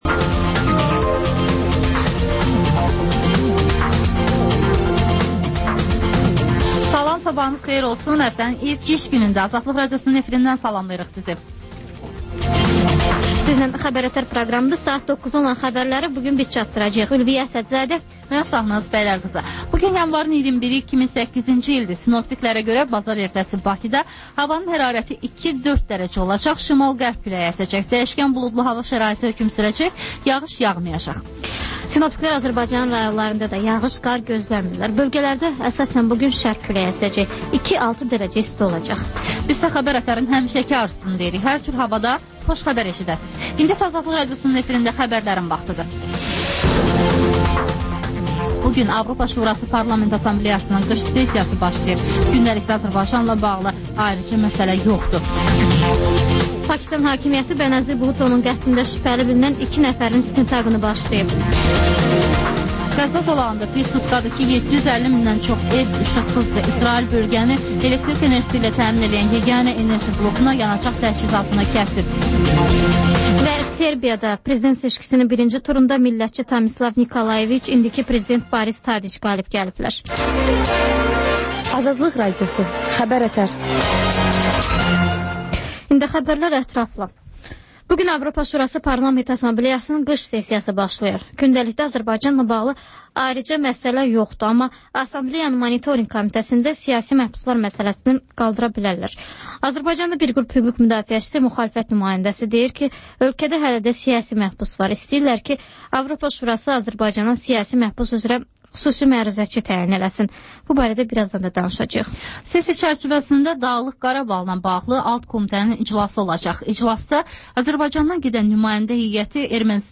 Xəbər-ətər: xəbərlər, müsahibələr, sonra PANORAMA verilişi: Həftənin aktual mövzusunun müzakirəsi